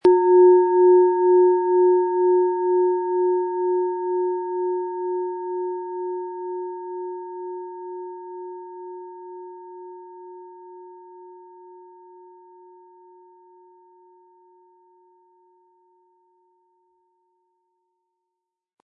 Antike Klangschale mit Wasser-Ton - für innere Beweglichkeit, Tiefe & fließende Emotionen
Kombiniert mit dem Mondton wirkt der Klang besonders umhüllend und ausgleichend.
Ihr Klang erinnert an fließendes Wasser im sanften Licht des Mondes - ruhig, tragend und voller Tiefe.
• Mittlerer Ton: Mond
Im Audio-Player - Jetzt reinhören hören Sie genau den Original-Klang der angebotenen Schale.
Ein schöner Klöppel liegt gratis bei, er lässt die Klangschale harmonisch und angenehm ertönen.